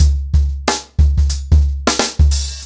TrackBack-90BPM.59.wav